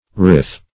ryth - definition of ryth - synonyms, pronunciation, spelling from Free Dictionary Search Result for " ryth" : The Collaborative International Dictionary of English v.0.48: Ryth \Ryth\ (r[i^]th), n. [Cf. AS. ri[eth] brook.]